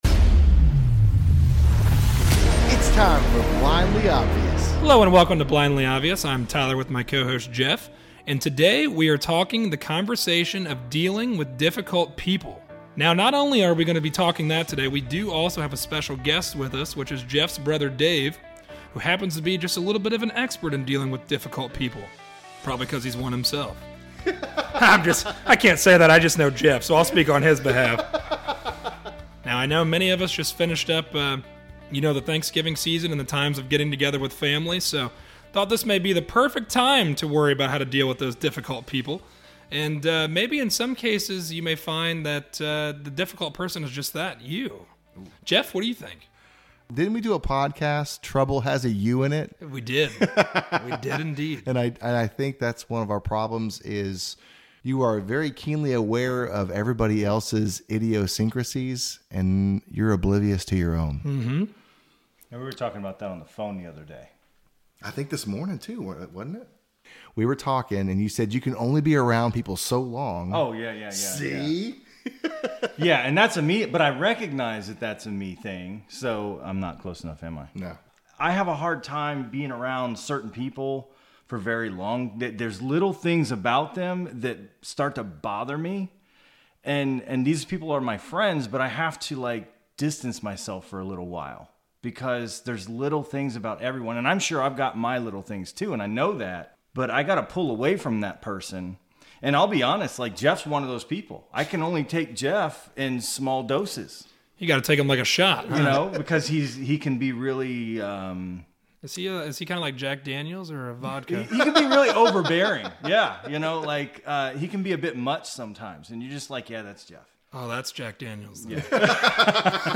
A conversation about people we love that can be difficult. How do we handle others or even ourselves when we prove to be difficult?